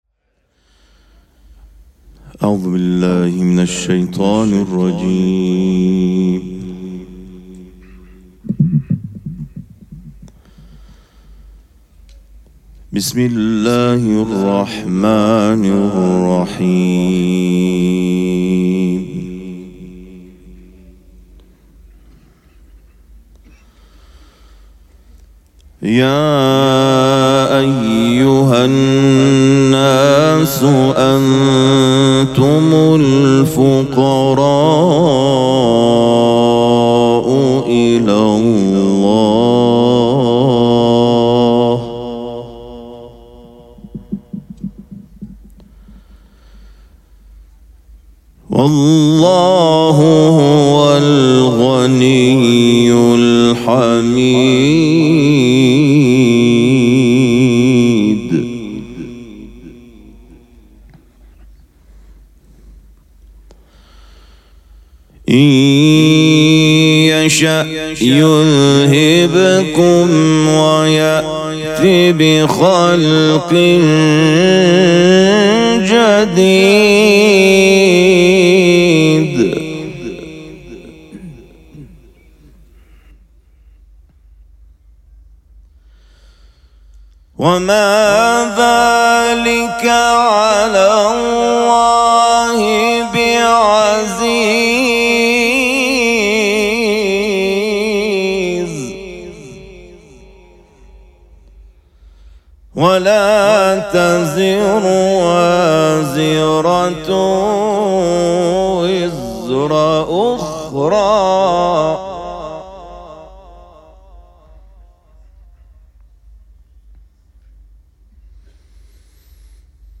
مراسم عزاداری وفات حضرت ام‌البنین سلام‌الله‌علیها
قرائت قرآن